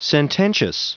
Prononciation du mot sententious en anglais (fichier audio)
sententious.wav